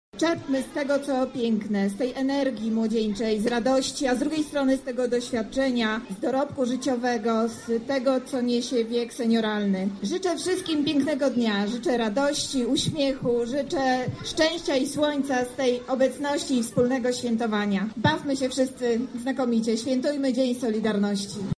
Pochód zakończyła część artystyczna na Placu Litewskim, gdzie można było wspólnie się bawić i śpiewać z innymi uczestnikami.
Do obecnych zwróciła się Monika Lipińska – Zastępca Prezydenta Lublina ds. Społecznych